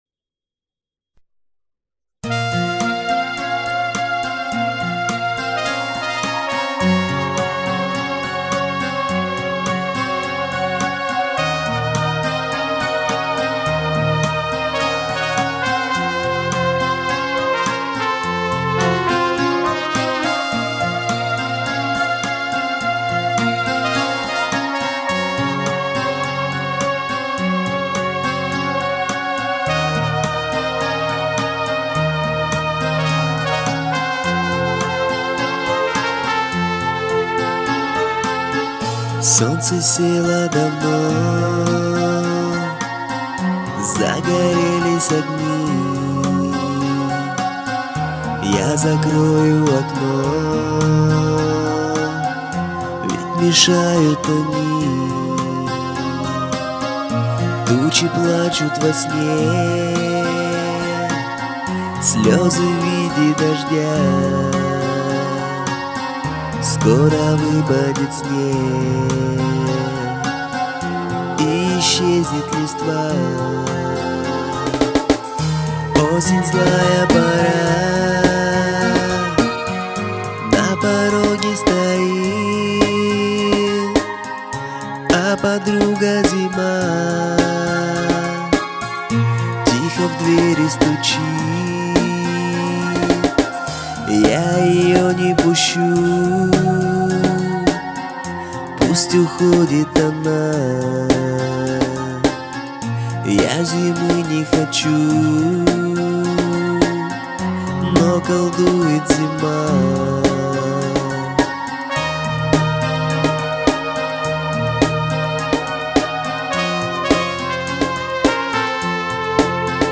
Комментарий соперника: простите за качество...